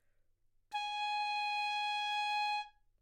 短笛单音（吹得不好） " 短笛B6不好的音符
描述：在巴塞罗那Universitat Pompeu Fabra音乐技术集团的goodsounds.org项目的背景下录制。单音乐器声音的Goodsound数据集。
标签： 好声音 单注 多样本 B6 纽曼-U87 短笛
声道立体声